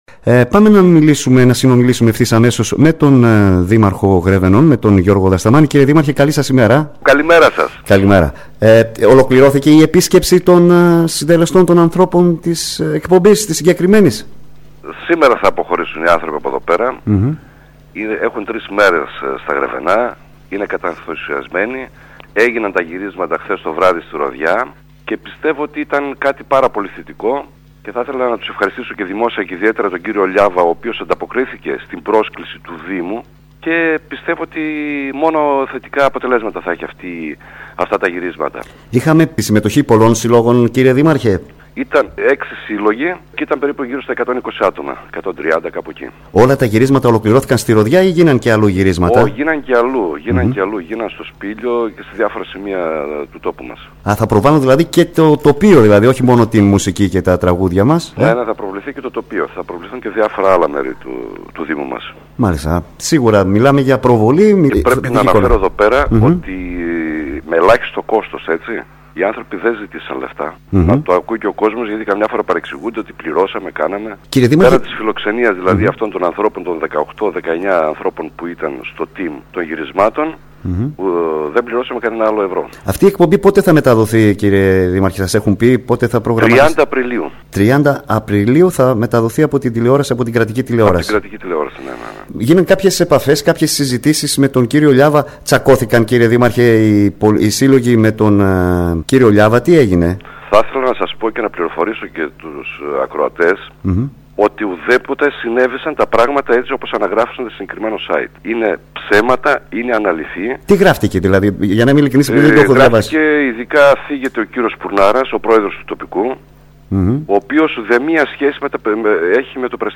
ΓΡΕΒΕΝΑ ΔΗΜΟΣ ΓΡΕΒΕΝΩΝ ΕΚΔΗΛΩΣΗ ΣΥΝΕΝΤΕΥΞΗ
Για την Εκπομπή το Αλάτι της Γης που παρουσιάζει ο Λάμπρος Λιάβας στην Κρατική Τηλεόραση (ΕΡΤ 1), και τους συντελεστές της που αποδέχθηκαν την πρόσκληση του Δήμου Γρεβενών, να έρθουν και να προβάλουν τα Γρεβενά, αναφέρθηκε την Πέμπτη (30-3) μιλώντας στον Star-fm 9 33, o Δήμαρχος Γρεβενών, Γιώργος Δασταμάνης: